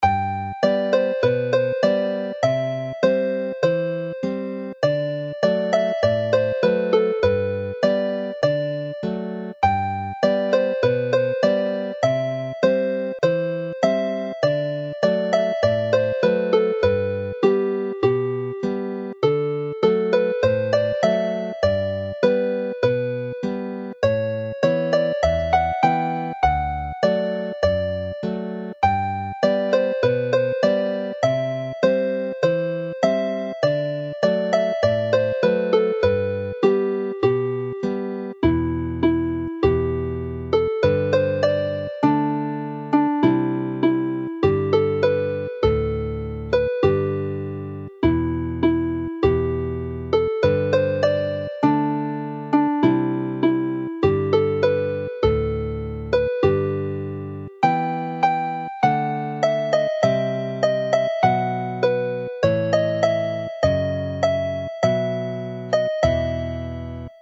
Two alternative tunes which are less musically sophisticated and easier to play, Aberllonydd and Aberawelon have been included here; both are simple and bring in a lighter feel to the dance before it returns to its formal musical base.